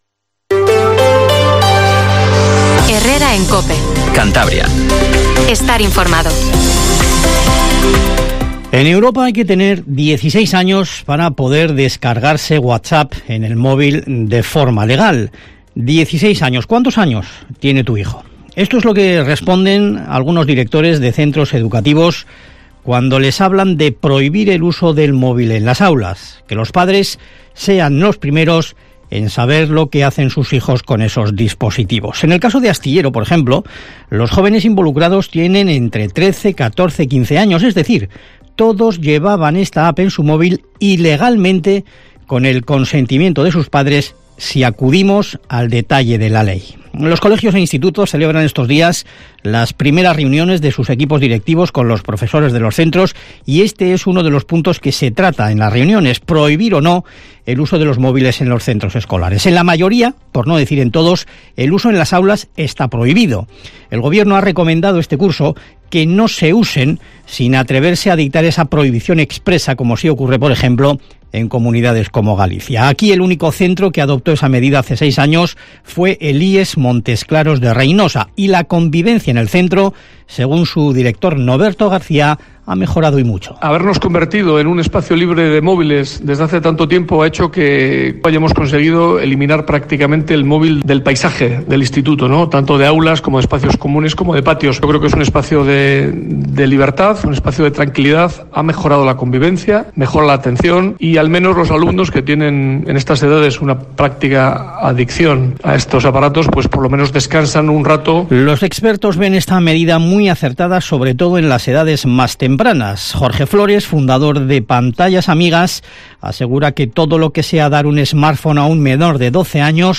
Informativo HERRERA en COPE CANTABRIA 07:50